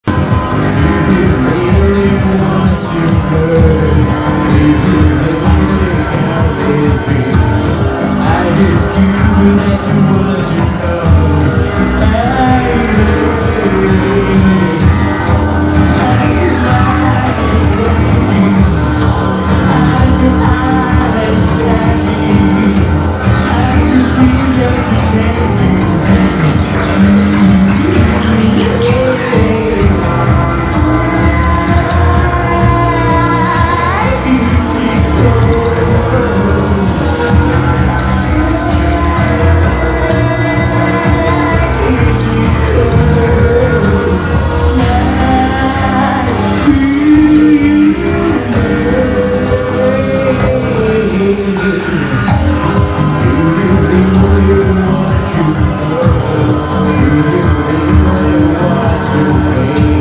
(MP3の音質は非常に悪いです。すみませんです。)